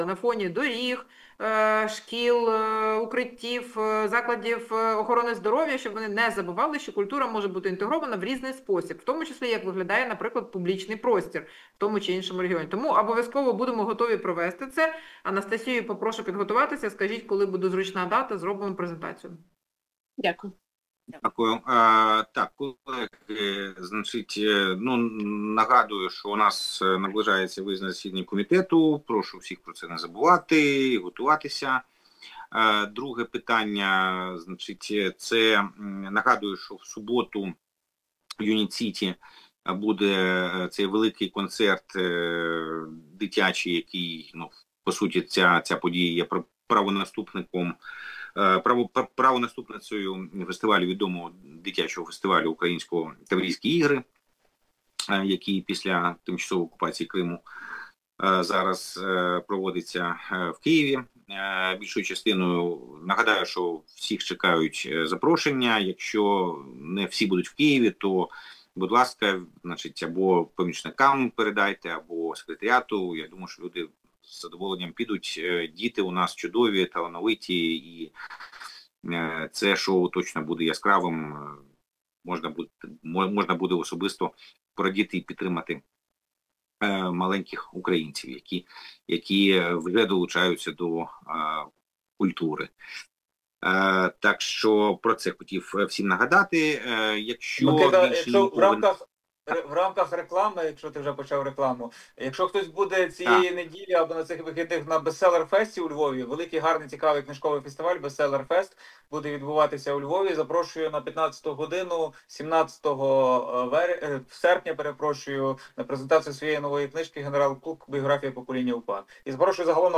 Назва файлу - Засідання Комітету 13 серпня 2025 року (2 частина)